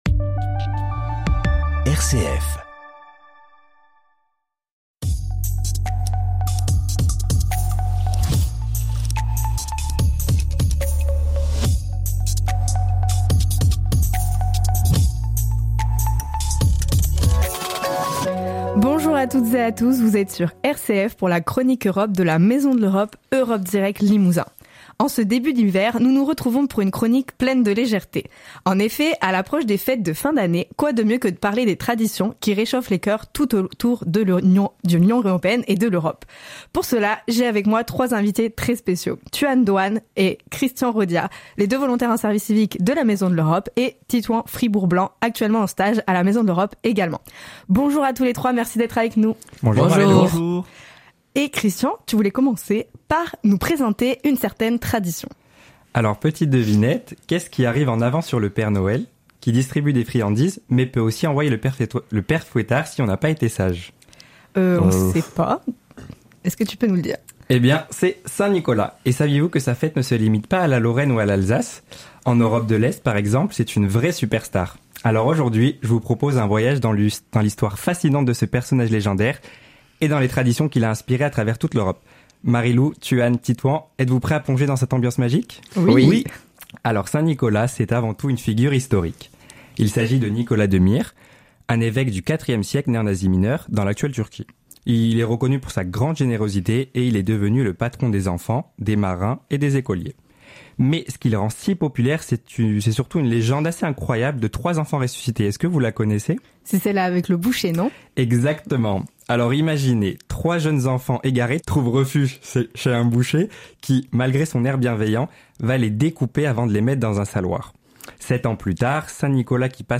Chronique-Europe-Maison-de-lEurope-RCF-Correze-01.mp3